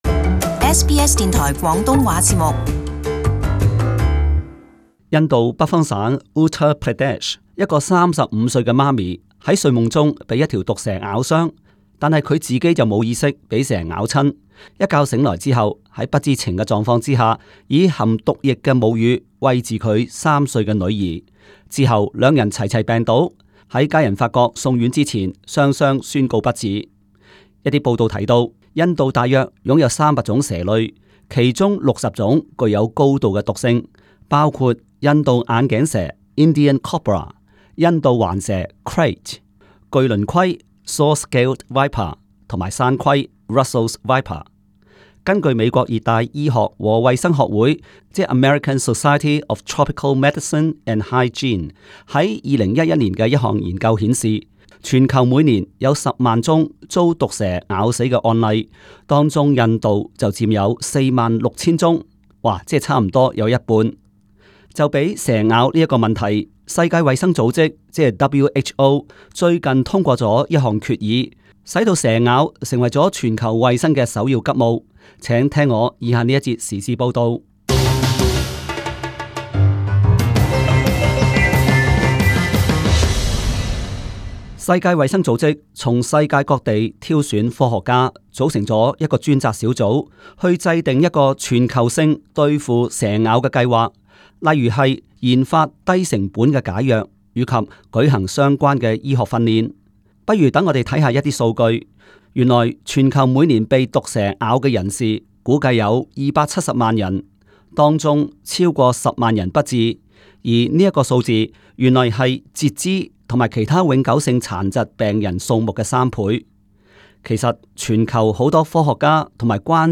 【時事報導】 世界衛生組織通過決議對付蛇咬